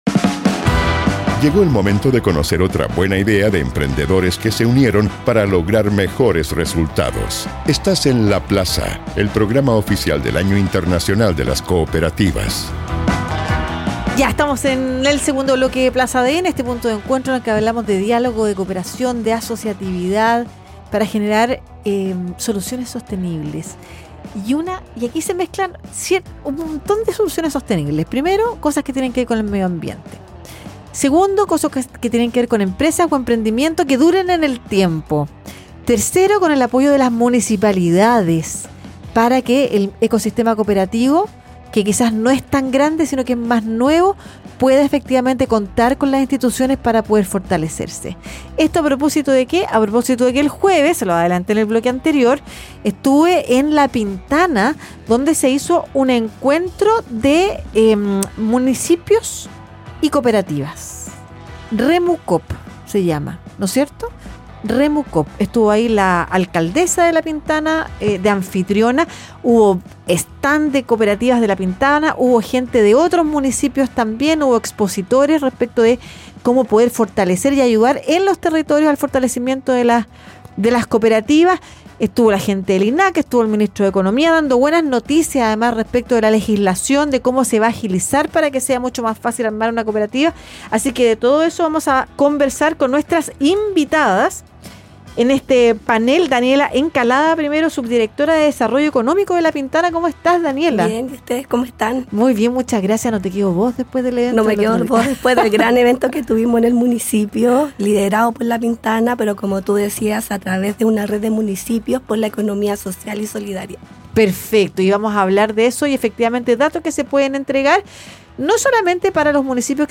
conversó con representantes de La Pintana.